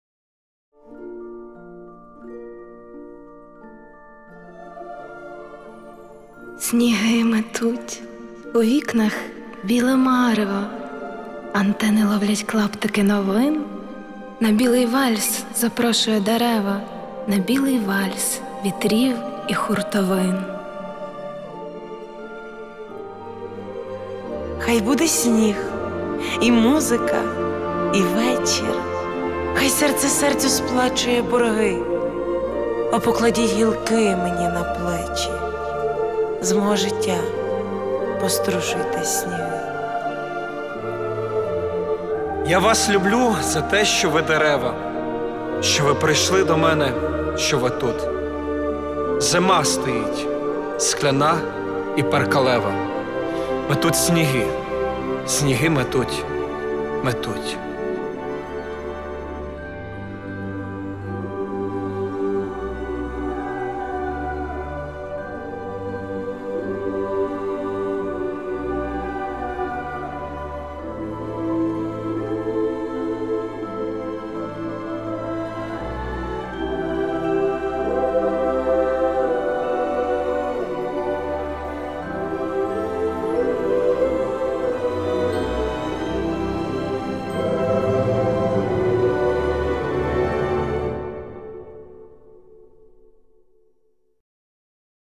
читаємо вірш Л. Костенко